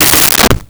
Toilet Seat Fall 03
Toilet Seat Fall 03.wav